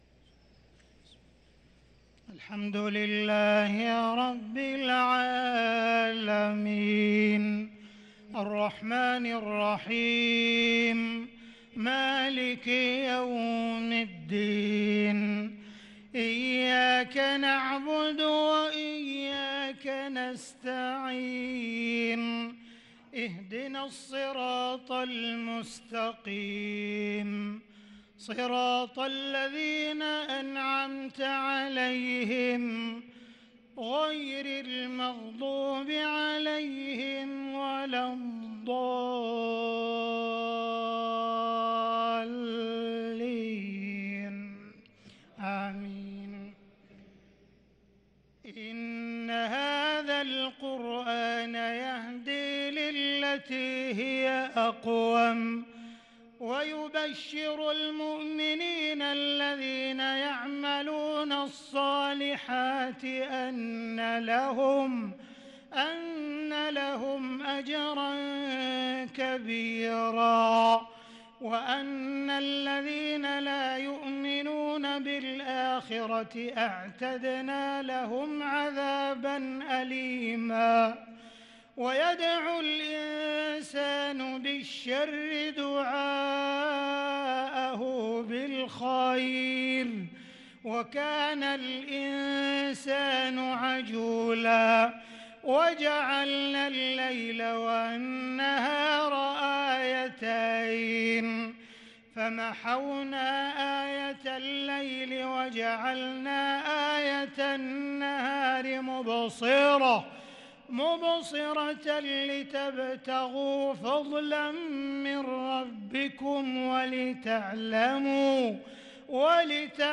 صلاة المغرب للقارئ عبدالرحمن السديس 10 رمضان 1443 هـ